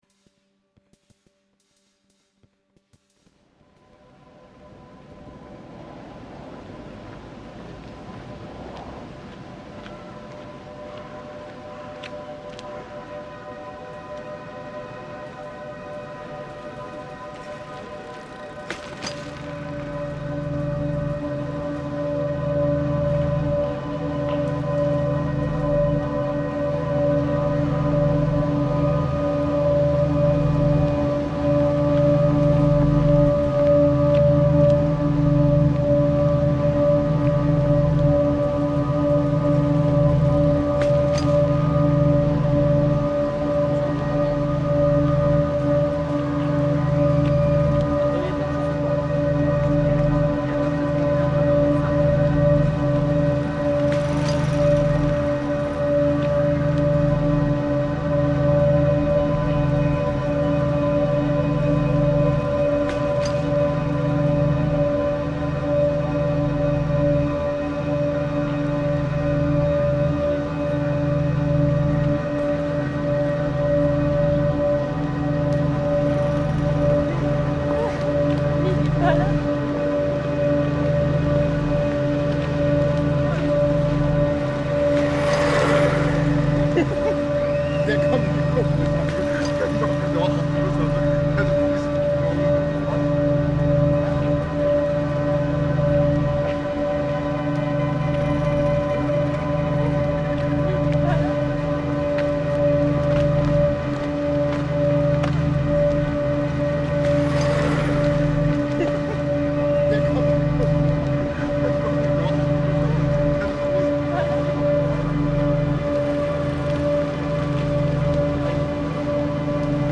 Sofienbergparken bench soundscape reimagined